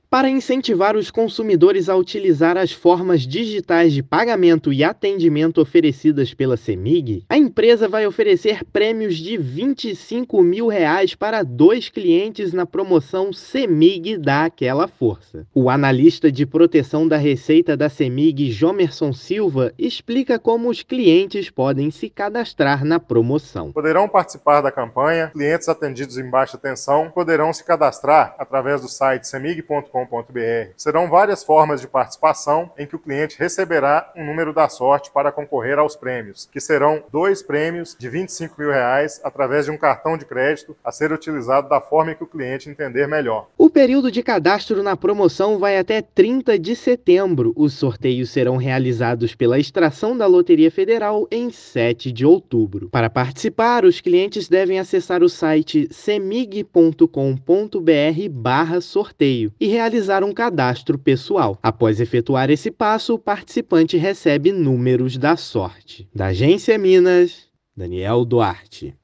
[RÁDIO] Promoção da Cemig vai sortear prêmios para clientes de Minas Gerais
Ouça a matéria de rádio: